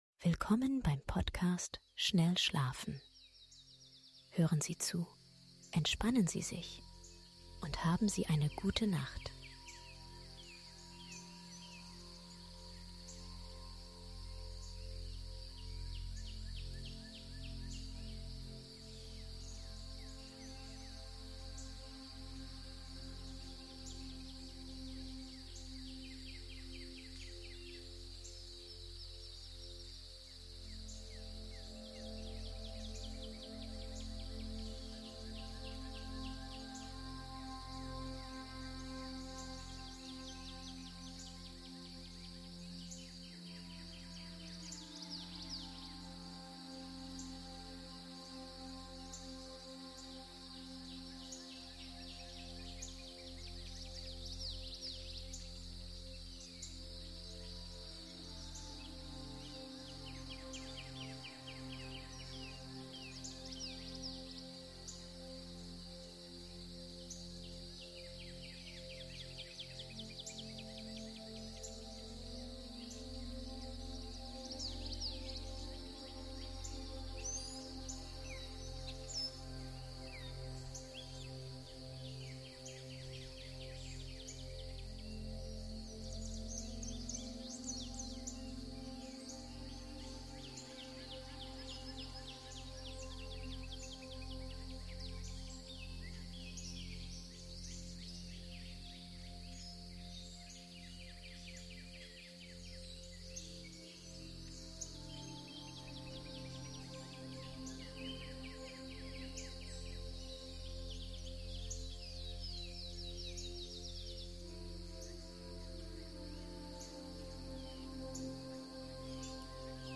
Zarte MUSIK und die Klänge der NATUR: ein Heilmittel gegen SCHLAFLOSIGKEIT